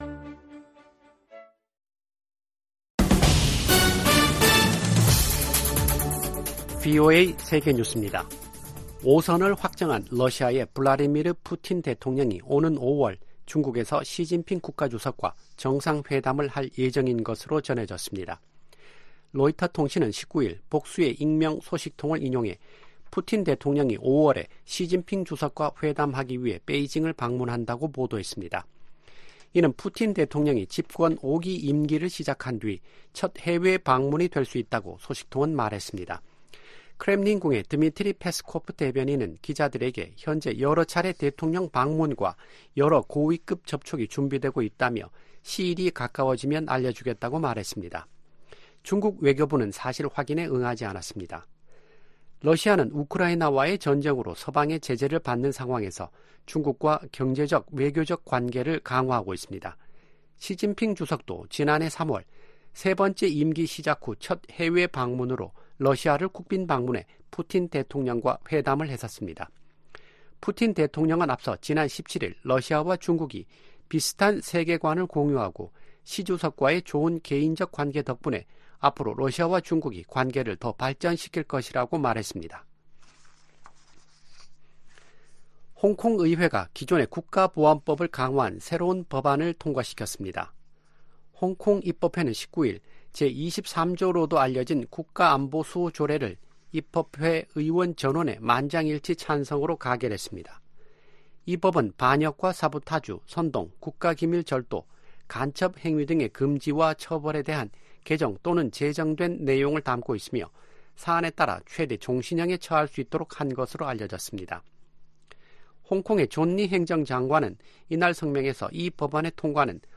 VOA 한국어 아침 뉴스 프로그램 '워싱턴 뉴스 광장' 2024년 3월 20일 방송입니다. 린다 토머스-그린필드 유엔 주재 미국 대사가 북한의 17일 단거리 탄도미사일 발사를 비판했습니다. 김정은 북한 국무위원장은 18일 한국 수도권 등을 겨냥한 초대형 방사포 사격훈련을 지도하며 위협 수위를 높였습니다. 미국 대선과 한국 총선을 앞둔 올해 북한의 임박한 공격 징후는 보이지 않는다고 정 박 미 국무부 대북 고위관리가 말했습니다.